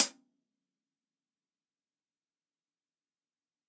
TUNA_HH_1.wav